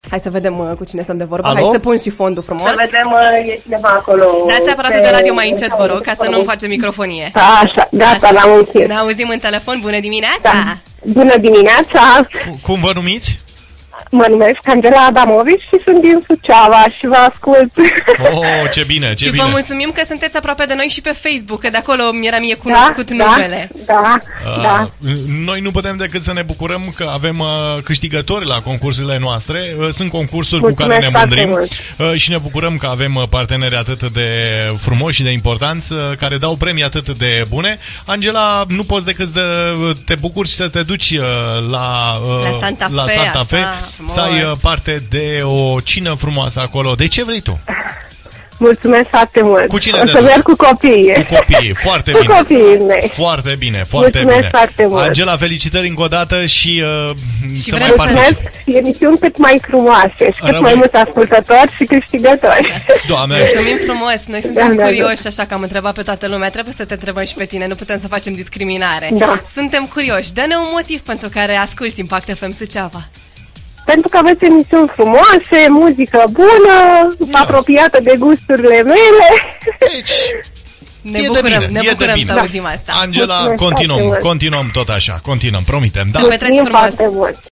Și pentru că și scopul nostru este să fim cât mai aproape de ascultători, nu ne-am putut abține să nu îi întrebăm pe cei cu care am intrat în direct care este motivul principal pentru care ne ascultă. Iată o parte din răspunsuri: